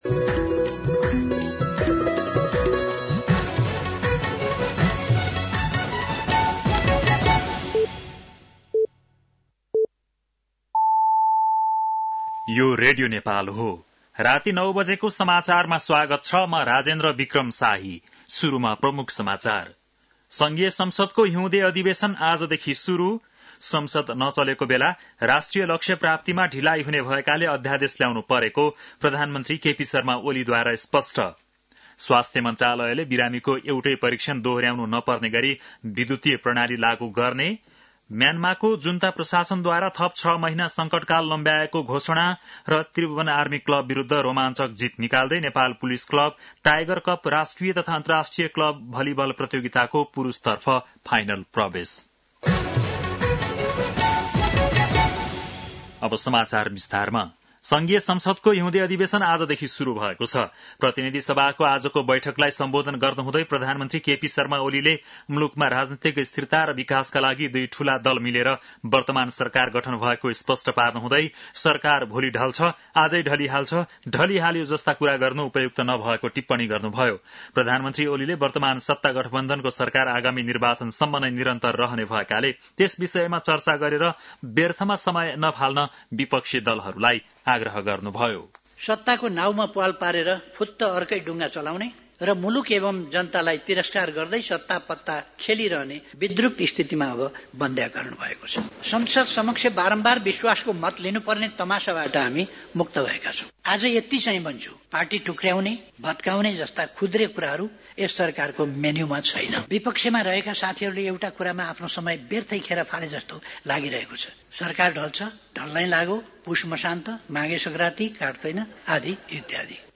बेलुकी ९ बजेको नेपाली समाचार : १९ माघ , २०८१